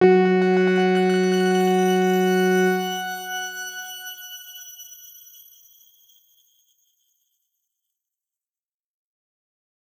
X_Grain-F#3-ff.wav